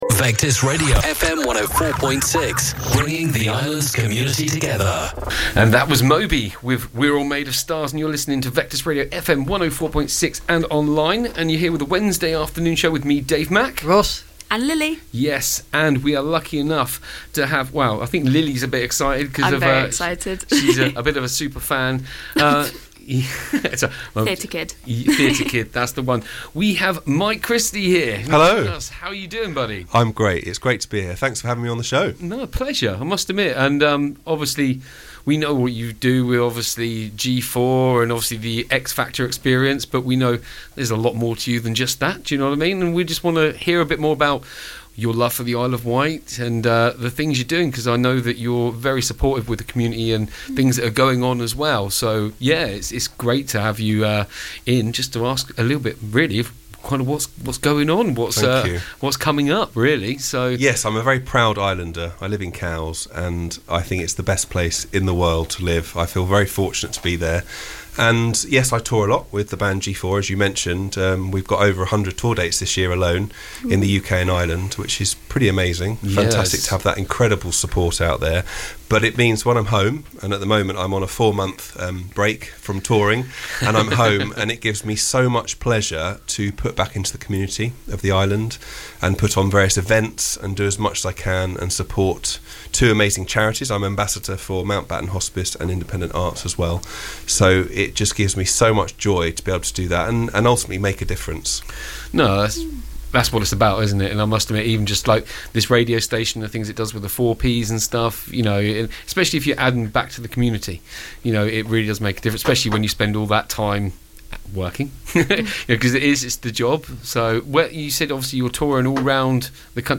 Vectis Interviews 2026